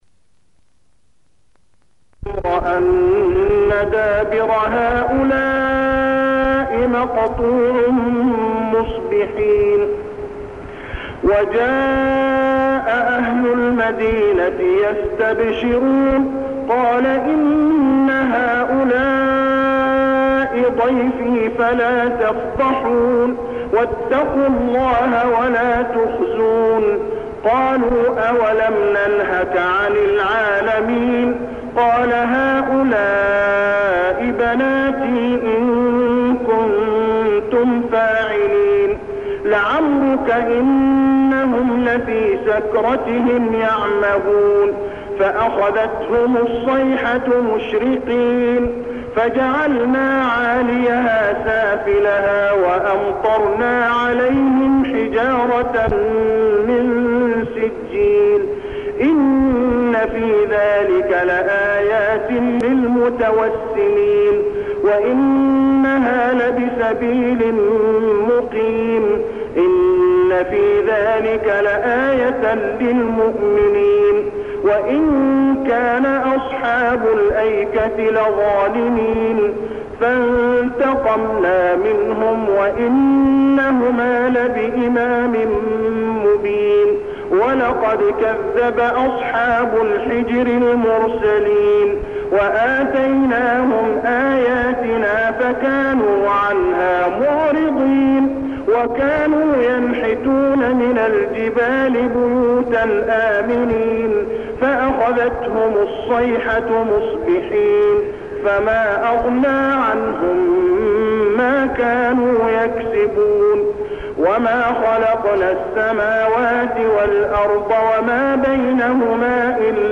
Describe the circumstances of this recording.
Listen a very beautiful and old recitation of Surah Al Hijr.